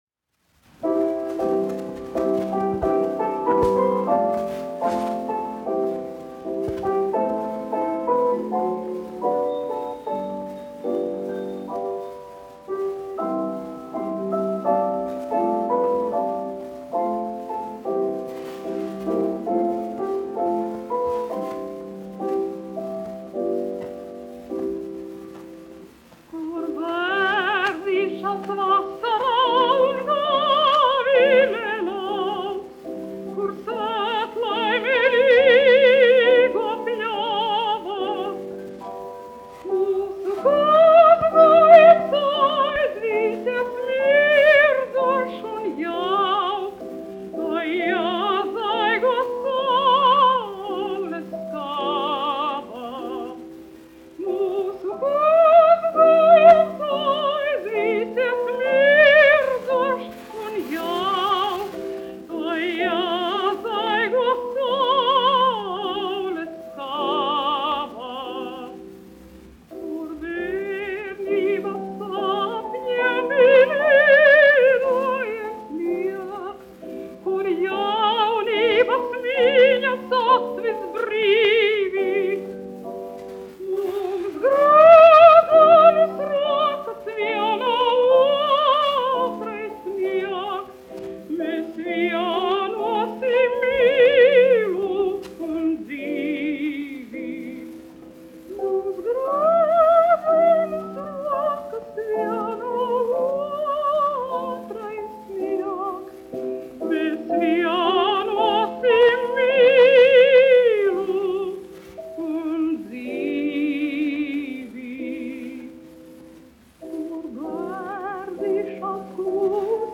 1 skpl. : analogs, 78 apgr/min, mono ; 25 cm
Somu tautasdziesmas
Skaņuplate
Latvijas vēsturiskie šellaka skaņuplašu ieraksti (Kolekcija)